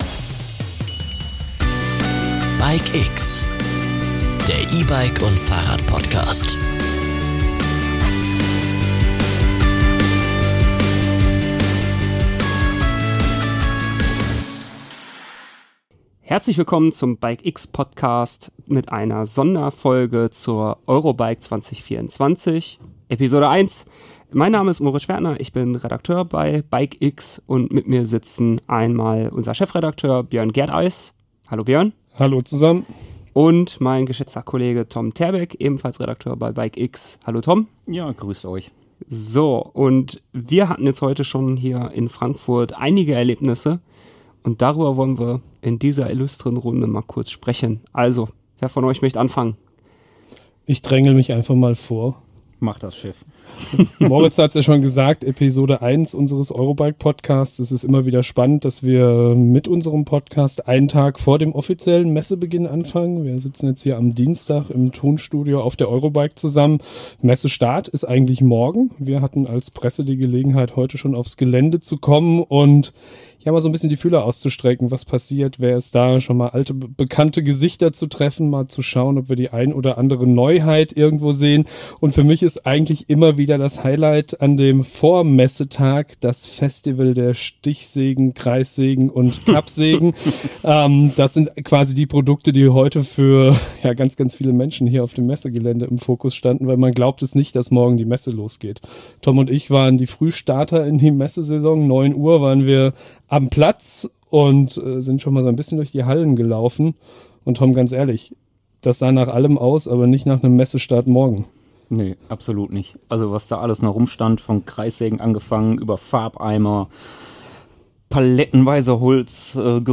Die Moderatoren teilen ihre Erfahrungen und Highlights, darunter die ersten Einblicke in neue Produkte wie Schwalbes innovatives Ventilsystem und den neuen Centrix Motor von ZF. Sie diskutieren die allgemeine Stimmung auf der Messe, die Herausforderungen und Chancen der Fahrradindustrie und die beeindruckenden Messestände, wie den von Riese & Müller.